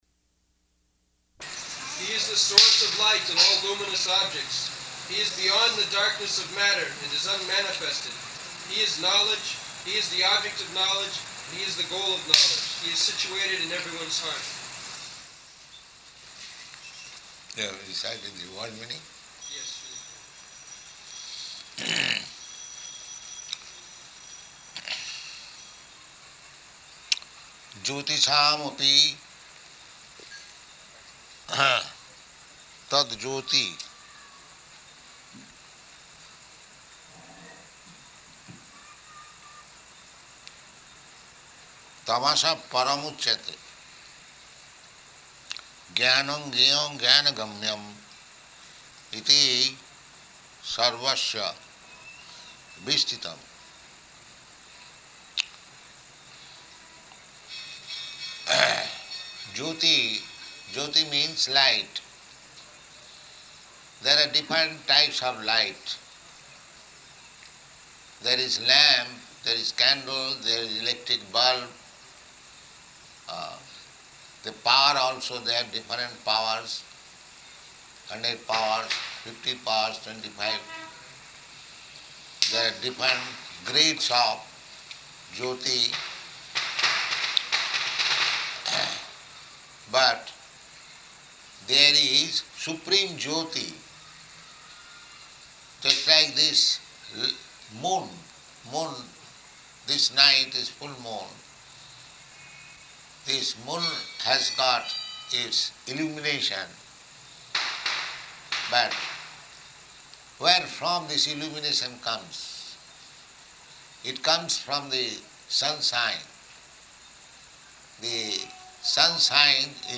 October 12th 1973 Location: Bombay Audio file
[loud sounds of firecrackers throughout] But there is supreme jyoti.